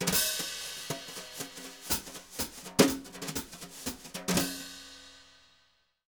Index of /90_sSampleCDs/Univers Sons - Jazzistic CD 1 & 2/VOL-1/03-180 BRUSH